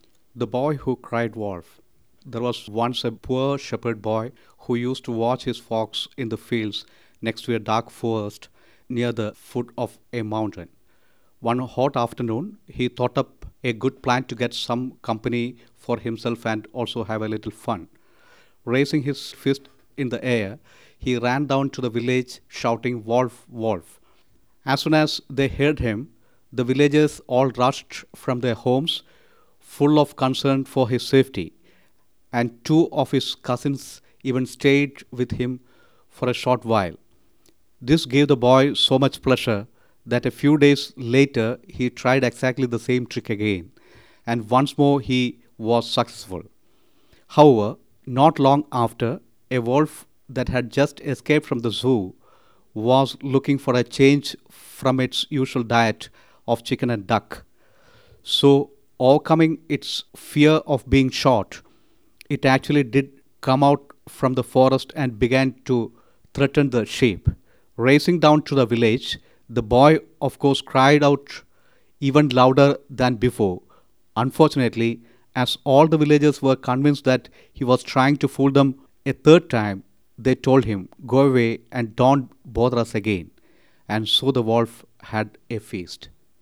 india-wolf.wav